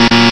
Buzzer1.ogg